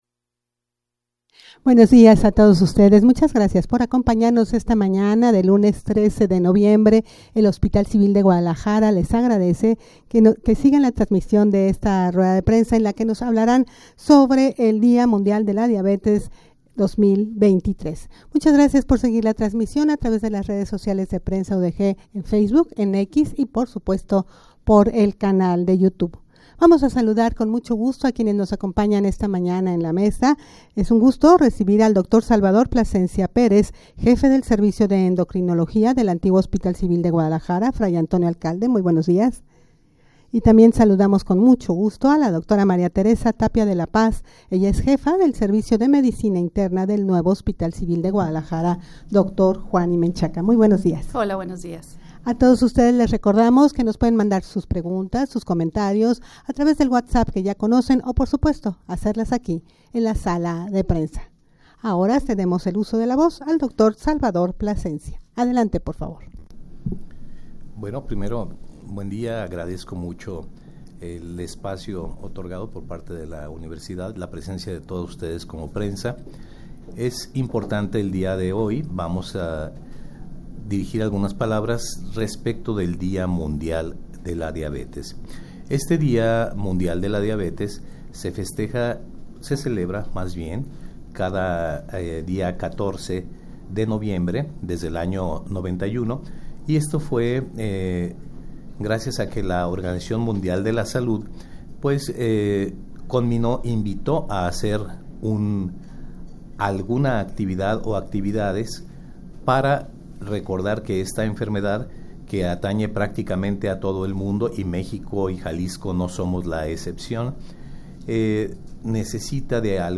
Audio de la Rueda de Prensa
rueda-de-prensa-con-motivo-del-dia-mundial-de-la-diabetes-2023.mp3